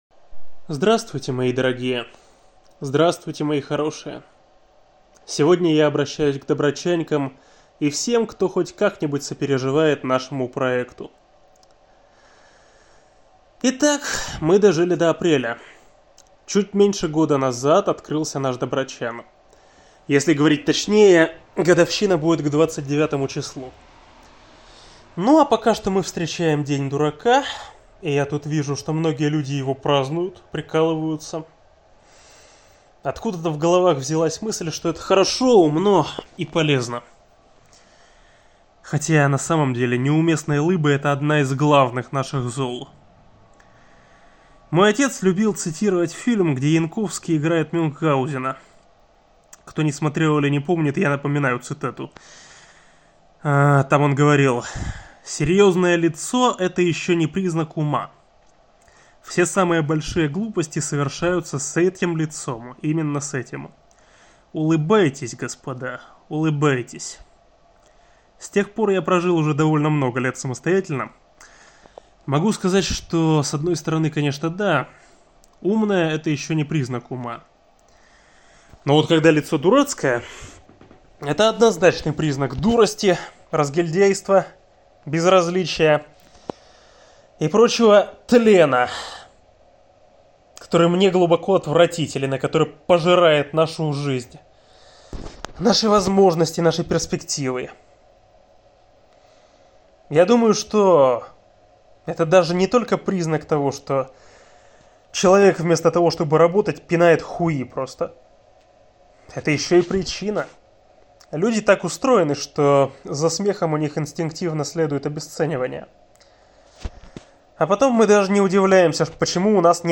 Опубликован 1 апреля 2025 в виде аудиосообщения (файл по ссылке)